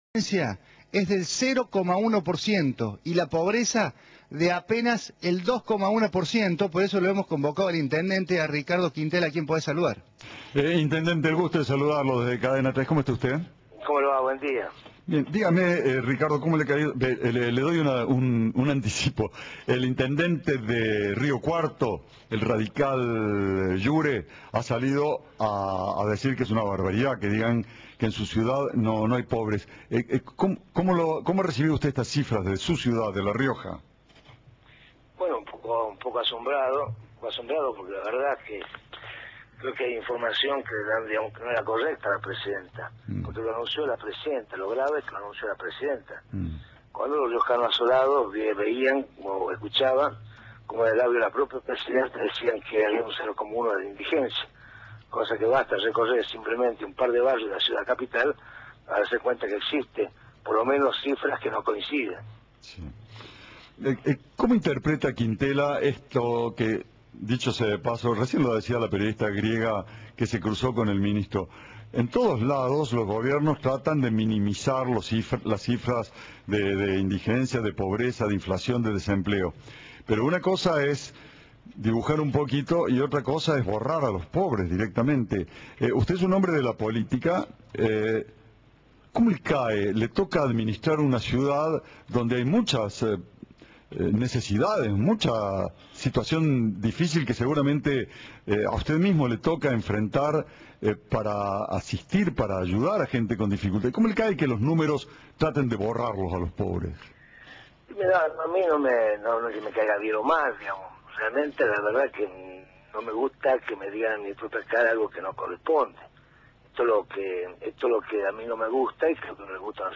En diálogo con Cadena 3 el intendente dijo que se siente «asombrado» por estos datos brindados por la Presidenta y señaló: «Creo que la información no es correcta».